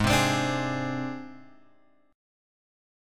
G# Major Flat 5th